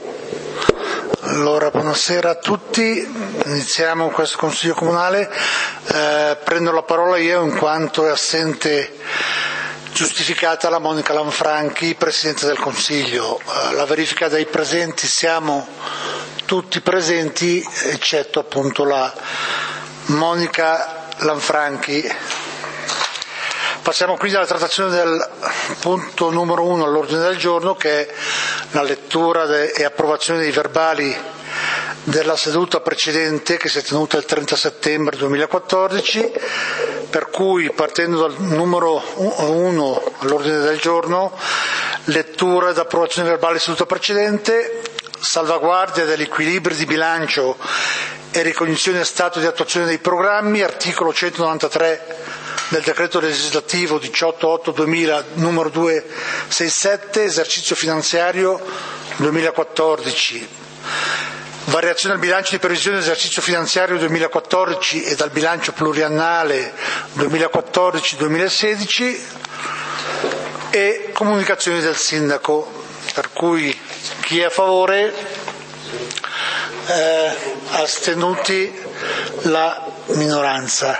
Consiglio comunale di Valdidentro del 25 Novembre 2014